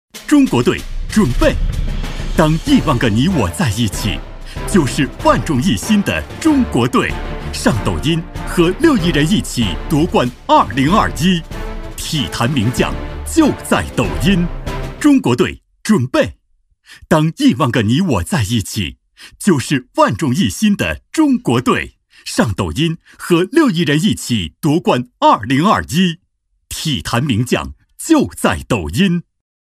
男14-抖音广告《中国队-上抖音》-激昂、大气
男14-透亮故事感 大气浑厚
男14-抖音广告《中国队-上抖音》-激昂、大气.mp3